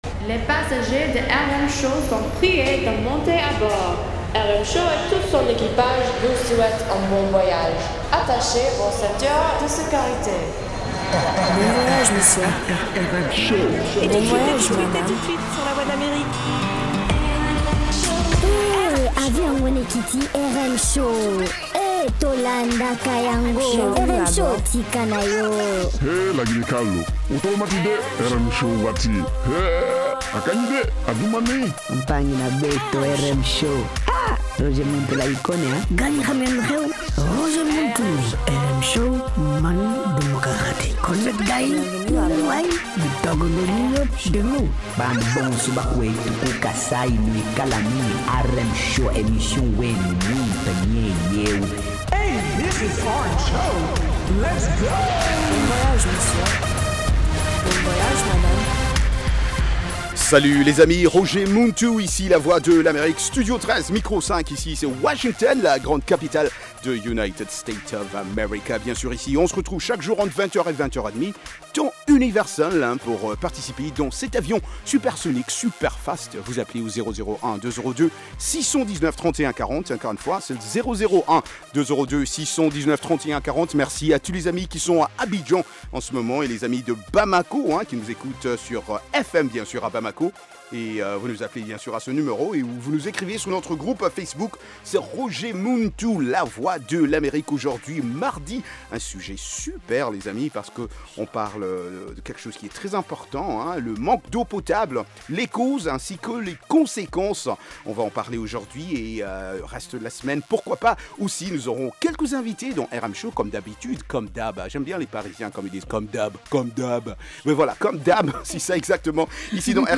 Cette émission est interactive par téléphone.